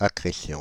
Ääntäminen
Ääntäminen Tuntematon aksentti: IPA: /kɾeθiˈmjento/ Haettu sana löytyi näillä lähdekielillä: espanja Käännös Ääninäyte Substantiivit 1. croissance {f} Muut/tuntemattomat 2. accrétion {f} Paris Suku: m .